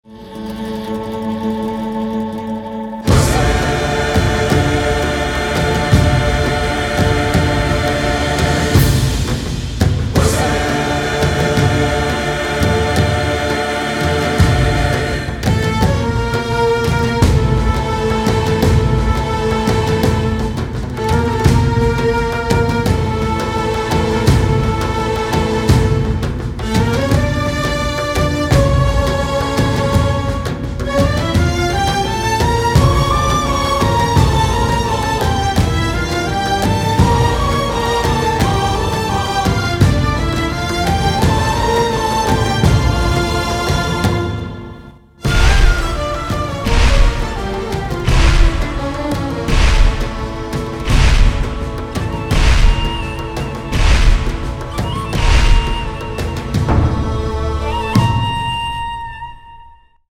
حسین | مداحی کربلایی محمدحسین پویانفر | تهیه شده توسط خانه هنر پلان 3